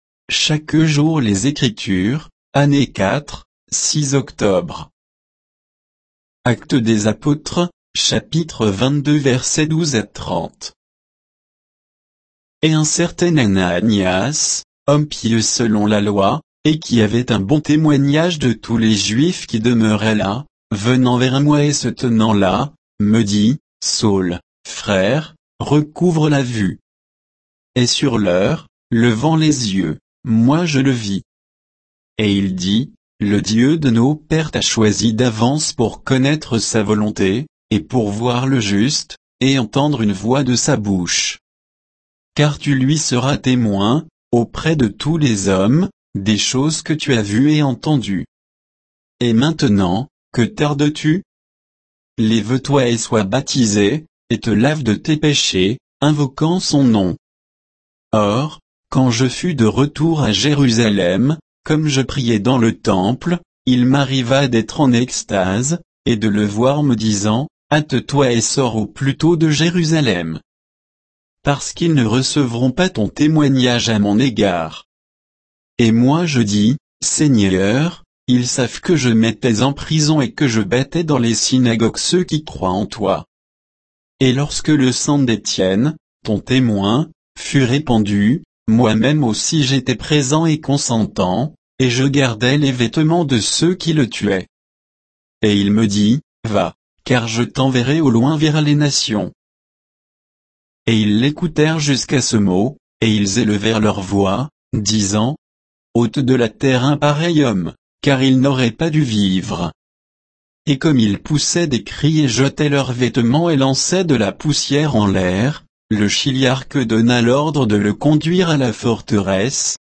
Méditation quoditienne de Chaque jour les Écritures sur Actes 22